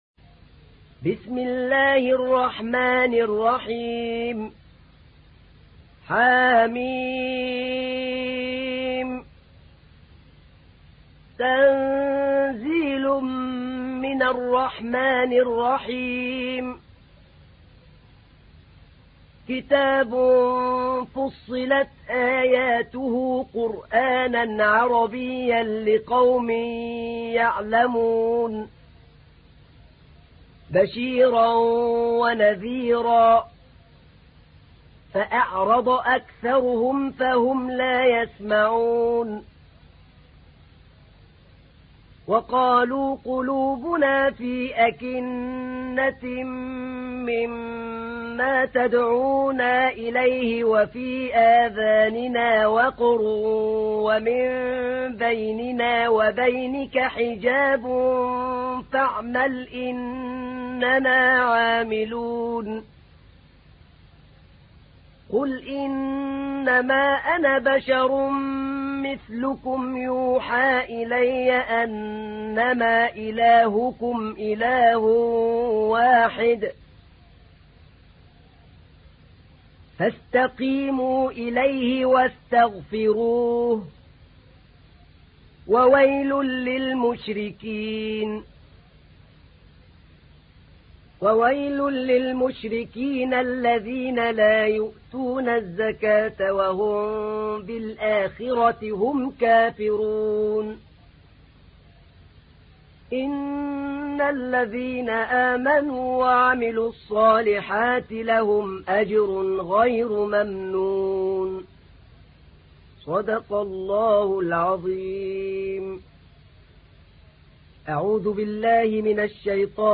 تحميل : 41. سورة فصلت / القارئ أحمد نعينع / القرآن الكريم / موقع يا حسين